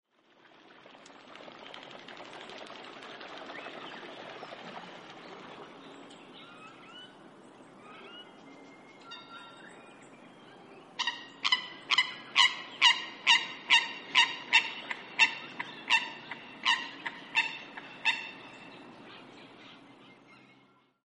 Eurasian Coot - Fulica atra
Voice: loud 'kerk', 'pit-pit' contact calls.
Call 2: birds chasing across the water, then 'kerk' calls
Eurasian_Coot2.mp3